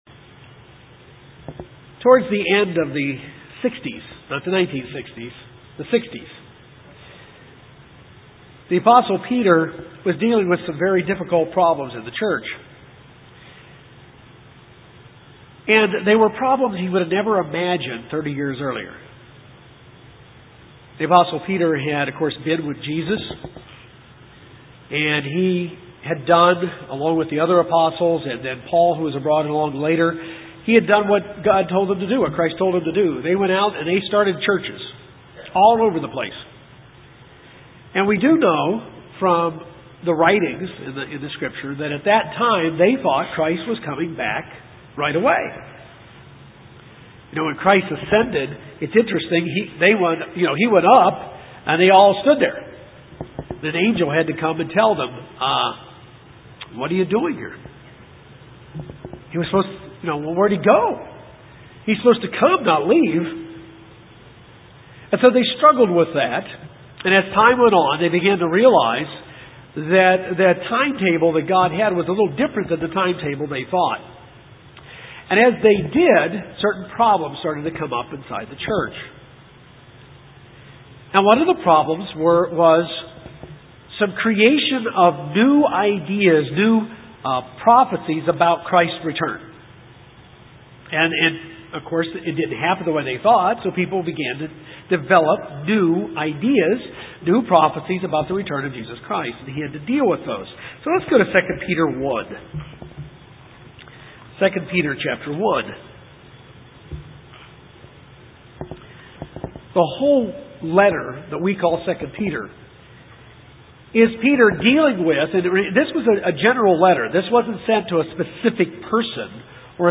This sermon looks at the warning to the Church of God.